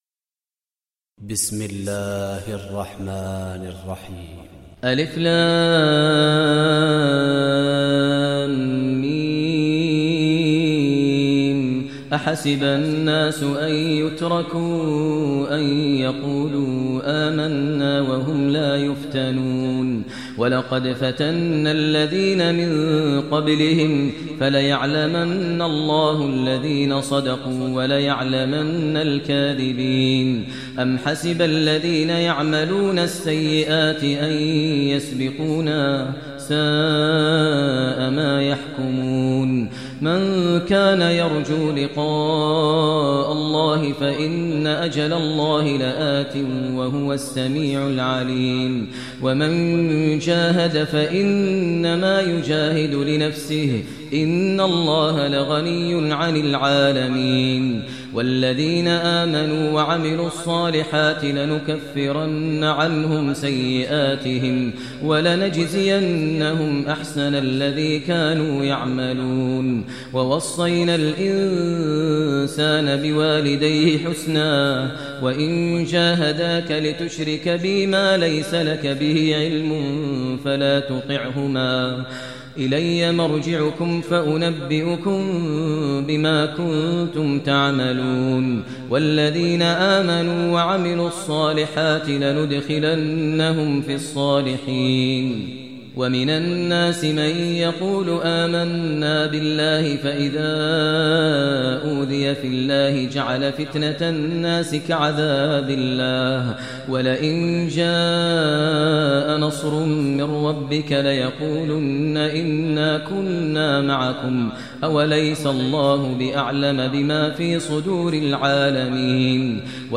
Surah Ankabut Recitation by Maher Mueaqly
Surah Ankabut, listen online mp3 tilawat / recitation in Arabic recited by Imam e Kaaba Sheikh Maher al Mueaqly.